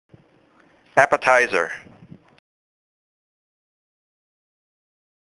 老美都是這麼唸！
這才是道地的美式發音喔！
重音在第一個音節，“tizer”部分只有次重音，不可作為主重音 。